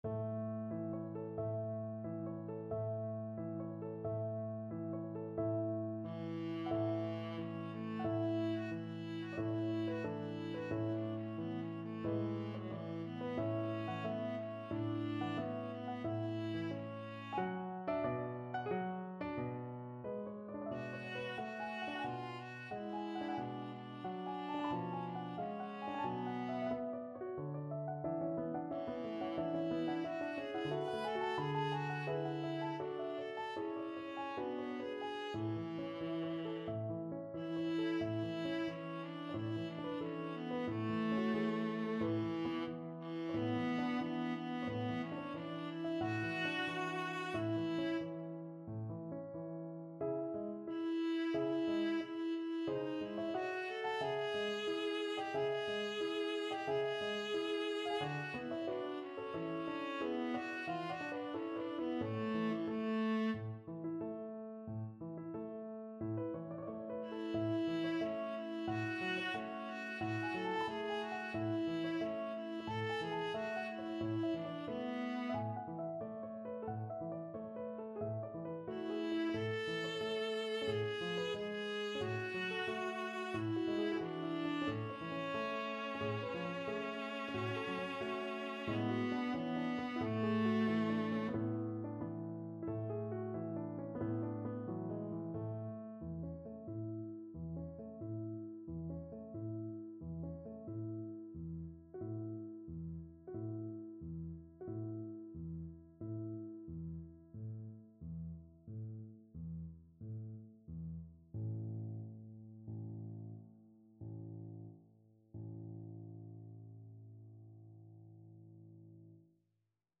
Viola
A major (Sounding Pitch) (View more A major Music for Viola )
Moderato =90
Classical (View more Classical Viola Music)